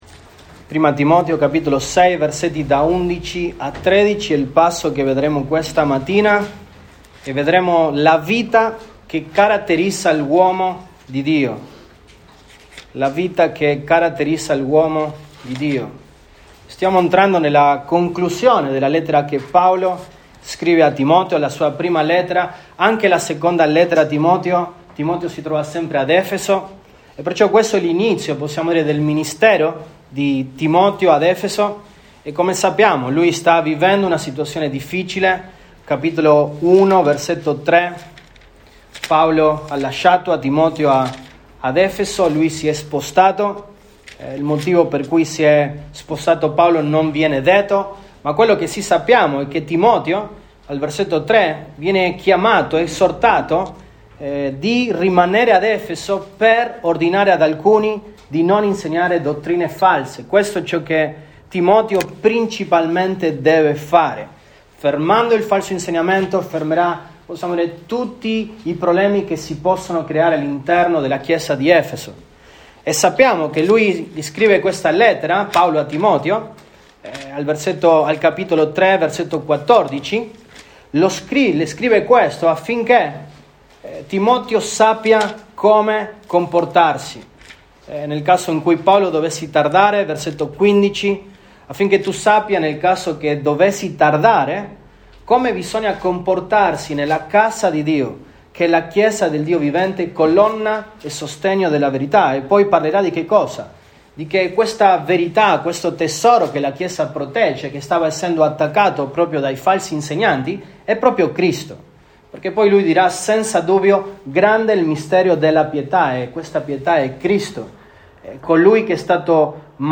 Feb 06, 2022 La vita che caratterizza l’uomo di Dio MP3 Note Sermoni in questa serie La vita che caratterizza l’uomo di Dio.